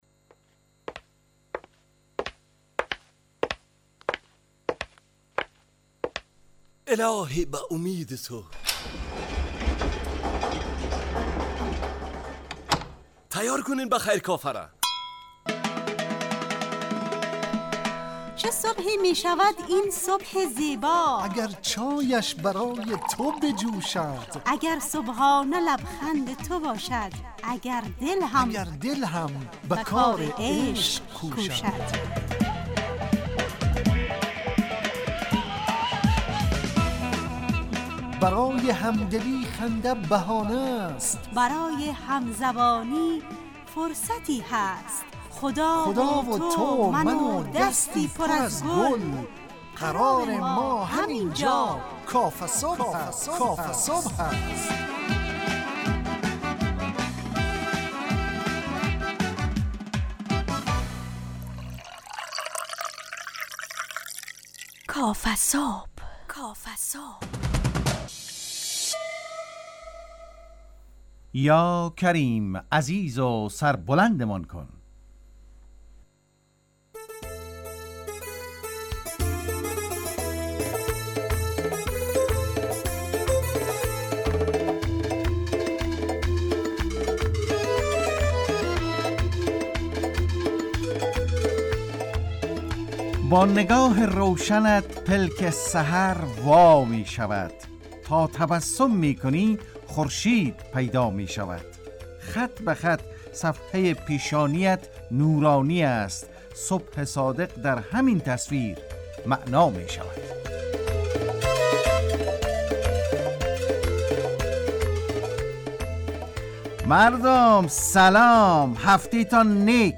مجله ی صبحگاهی رادیو دری با هدف ایجاد فضای شاد و پرنشاط صبحگاهی